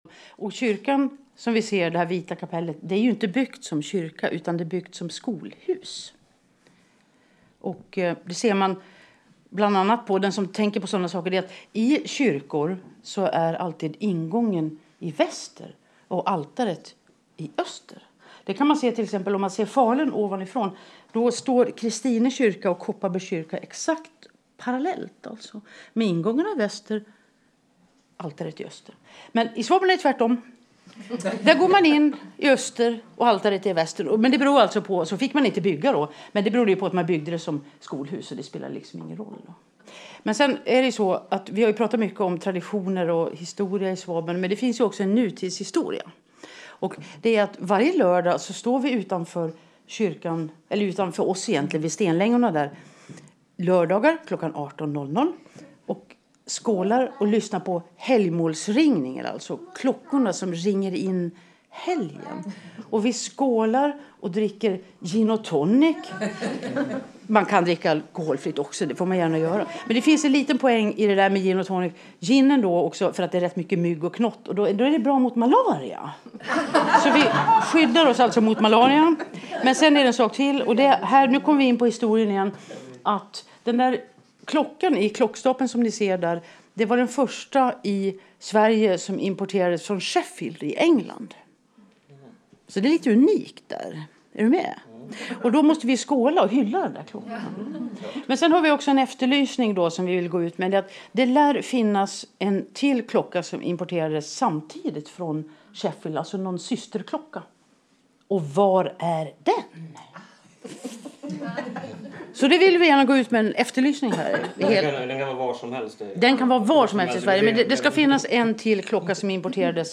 Vi samlas i bygdeföreningens gård nere vid Kolhusudden.